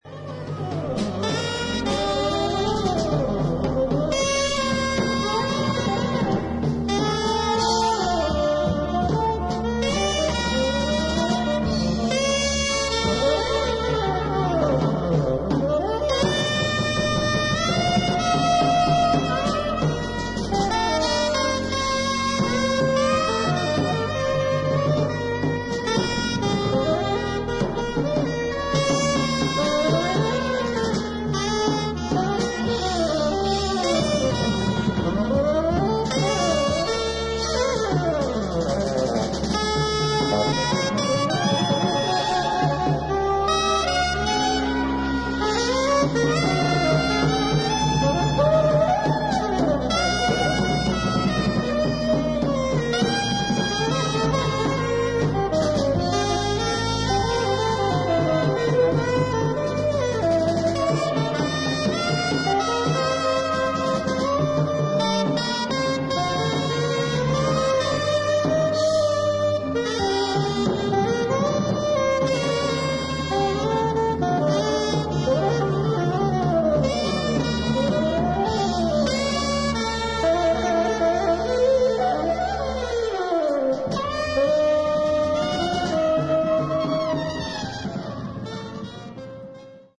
1969年にニューヨーク大学にて録音。
アルトサックス、トランペット、ヴァイオリン
コルネット、フルート
ベース
クラリネット、テナーサックス